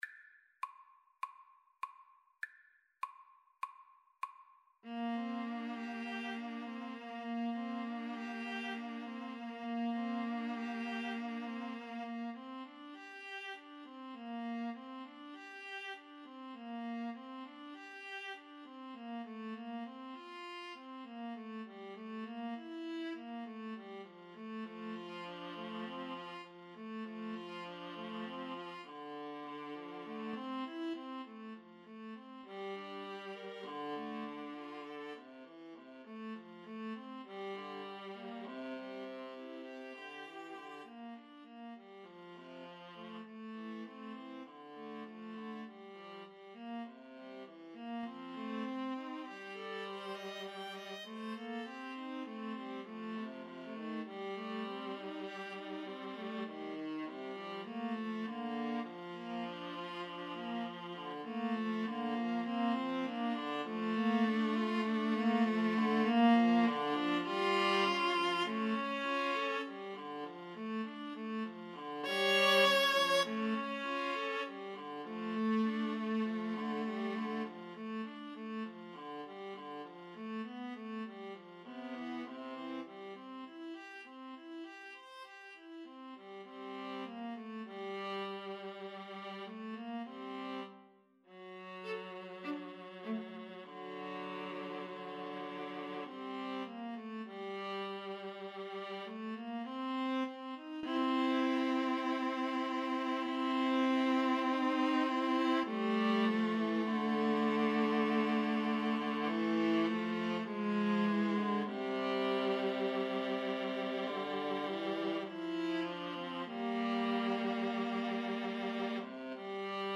4/4 (View more 4/4 Music)
Andantino sans lenteur (View more music marked Andantino)
Classical (View more Classical Viola Trio Music)